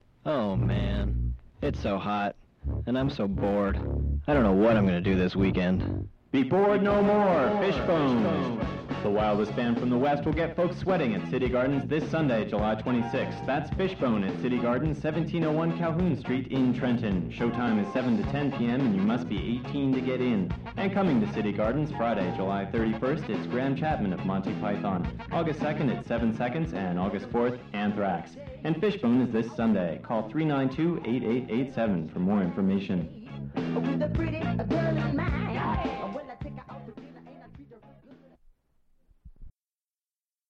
Fishbone_City_Gardens_promo.mp3